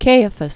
(kāə-fəs, kī-), Joseph fl. first century AD.